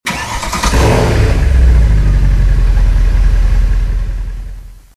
Home gmod sound vehicles tdmcars slsamg
enginestart.mp3